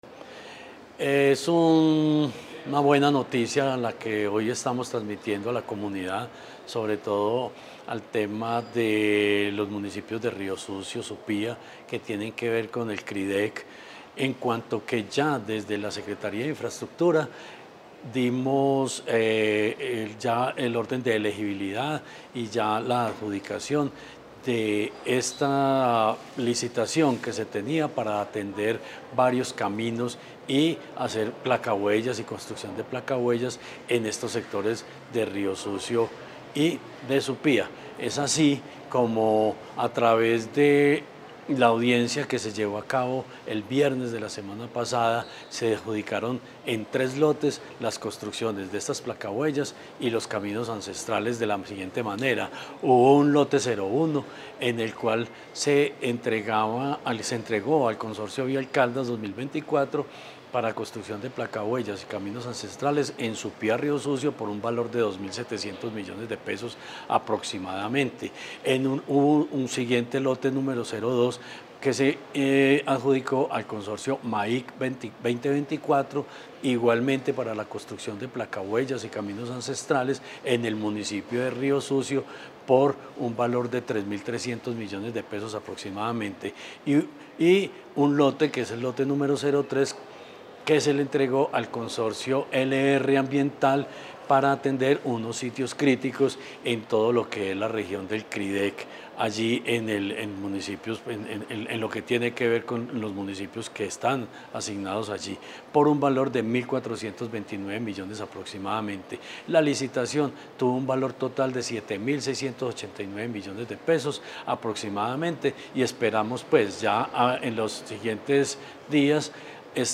Jorge Ricardo Gutiérrez, Secretario de Infraestructura de Caldas.
Jorge-Ricardo-Gutierrez-adjudicacion-CRIDEC.mp3